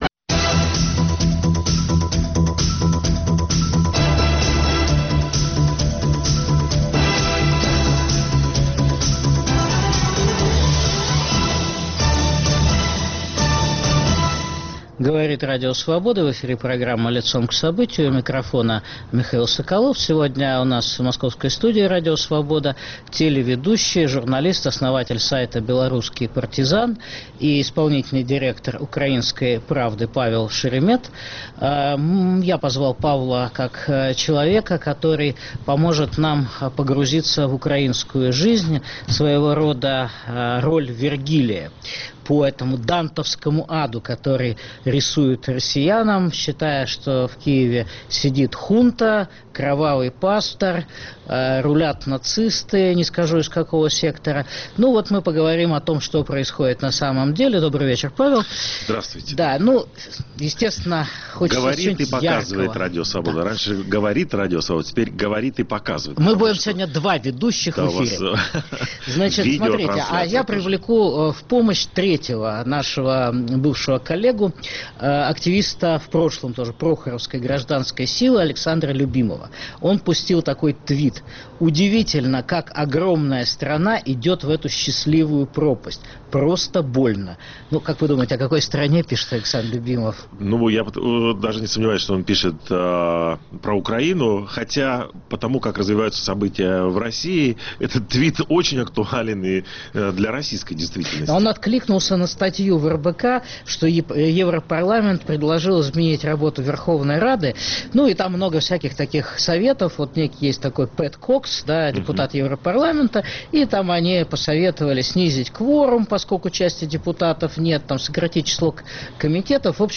Гость программы - телеведущий и кинодокументалист Павел Шеремет.